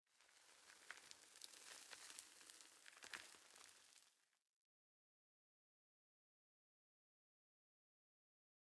sand12.ogg